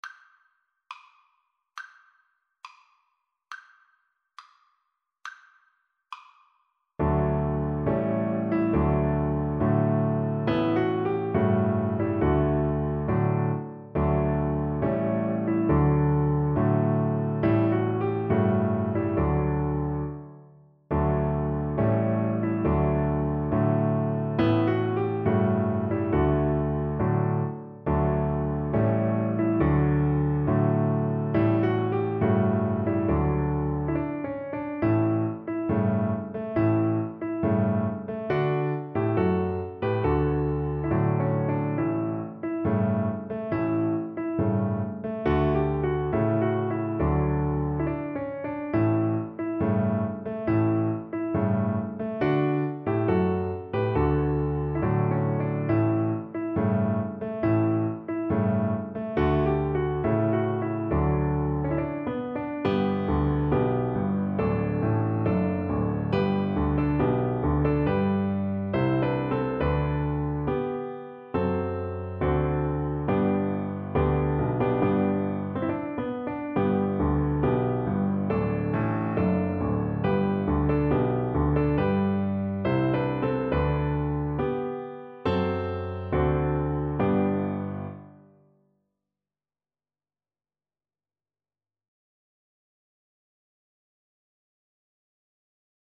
Violin
D major (Sounding Pitch) (View more D major Music for Violin )
March
2/4 (View more 2/4 Music)
Classical (View more Classical Violin Music)
philippine_nat_vln_kar3.mp3